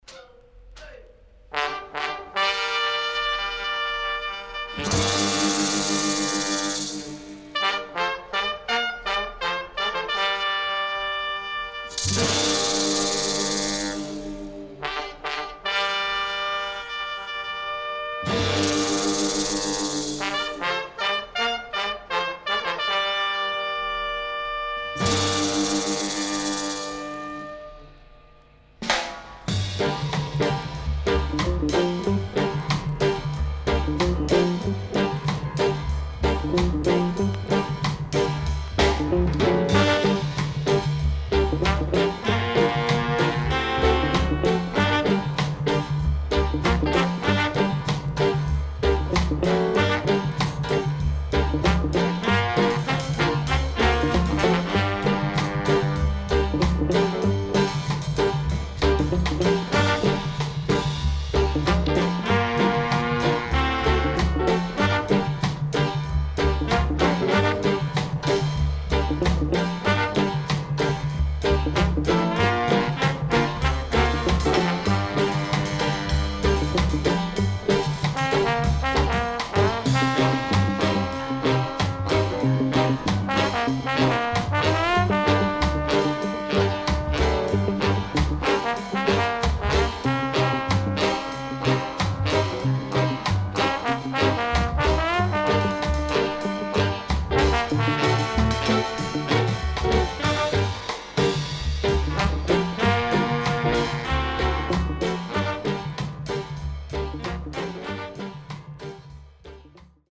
SIDE A:盤質は良好です。盤面はわずかにうすい擦れ傷あり。